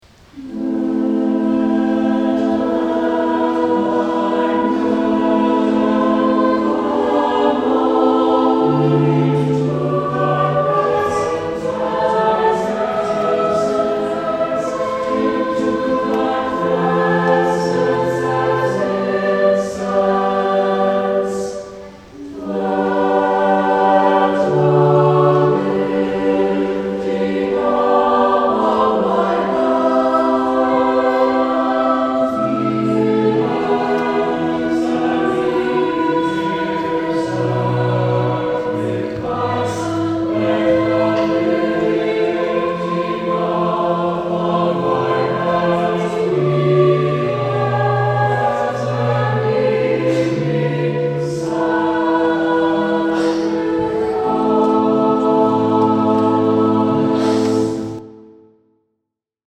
Saint Clement Choir Sang this Song
Anthem